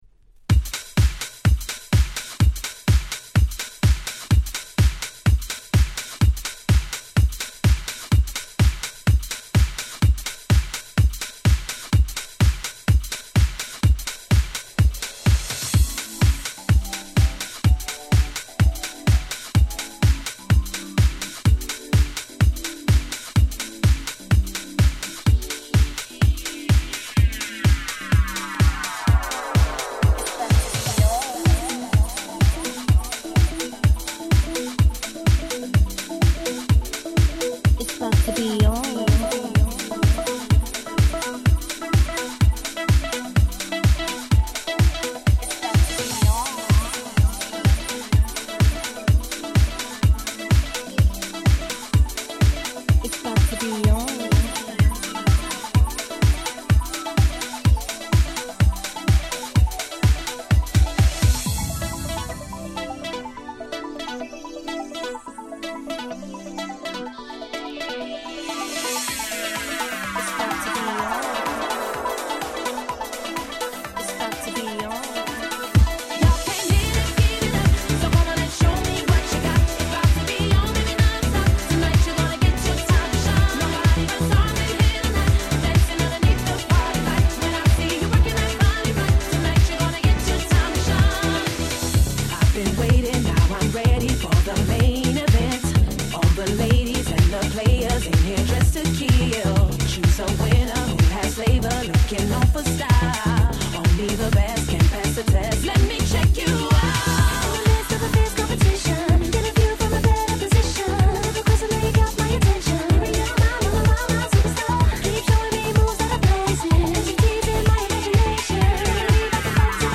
Super Hit Vocal House !!